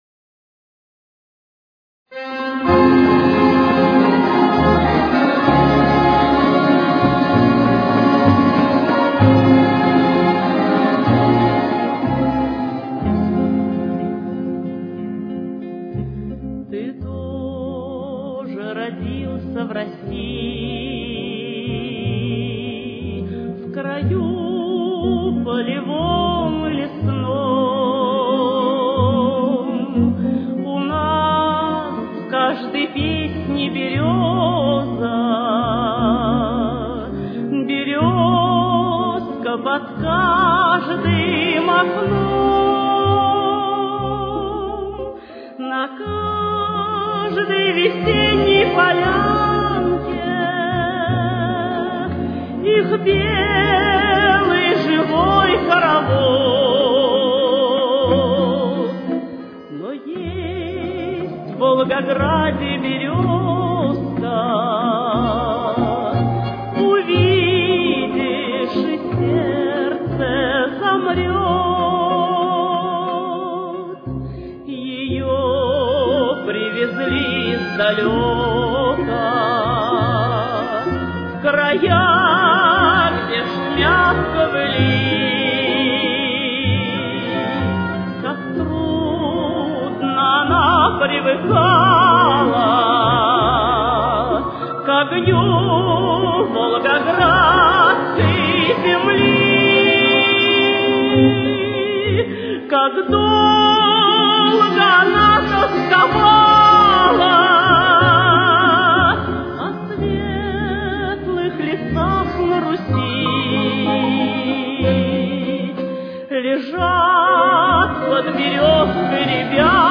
Фа минор.